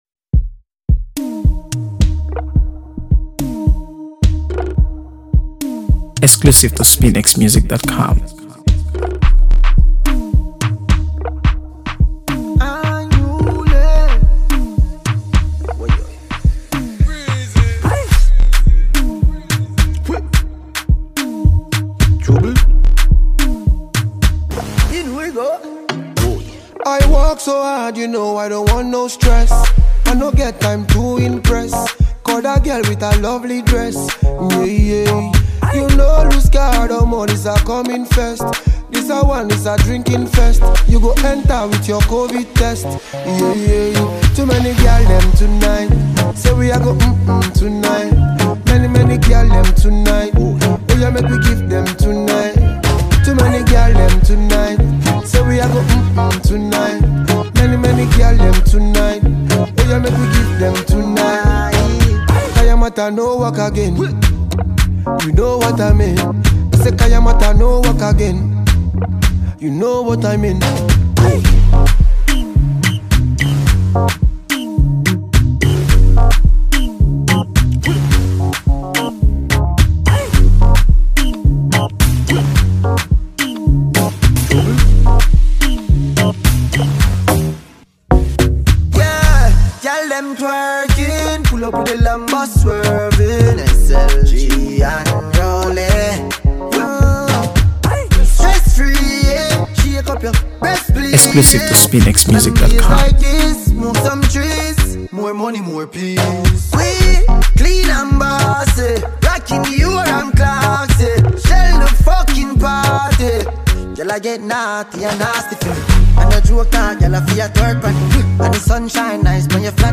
By on Afrobeat